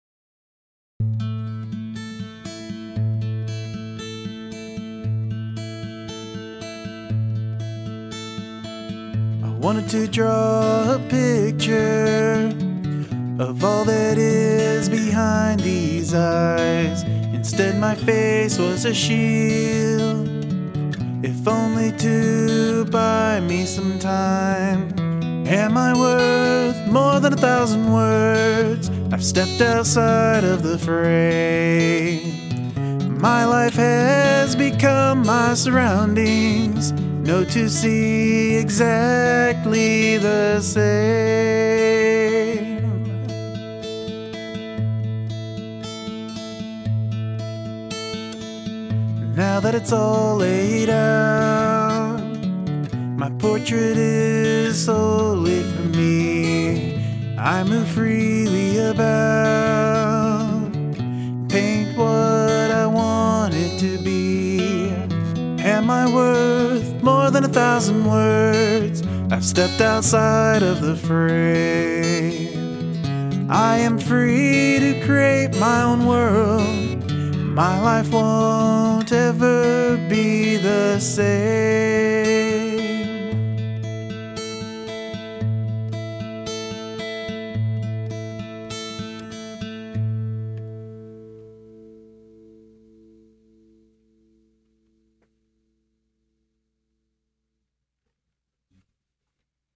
Love the tone on your vocal and that Skillfull guitar!
Very nice, love the guitar and the way it's put together.